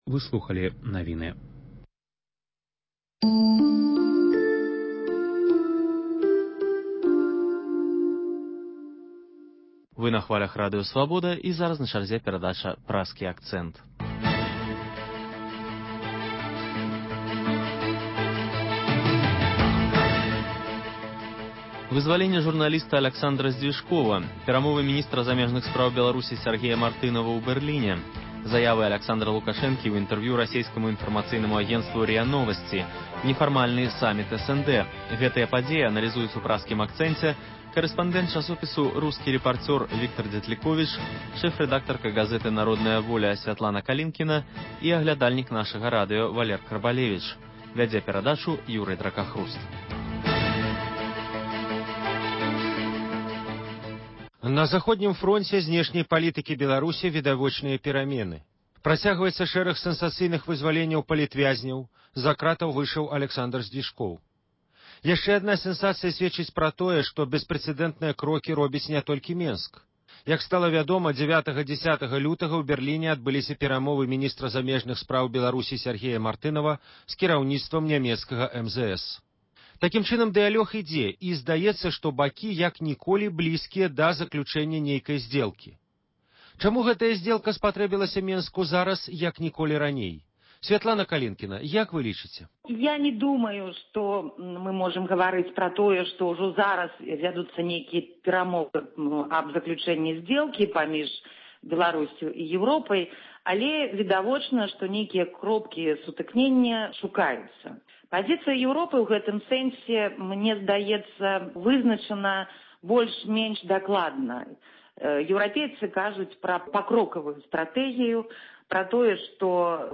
Круглы стол крытыкаў, прысьвечаны 115-годзьдзю з дня нараджэньня Максіма Гарэцкага.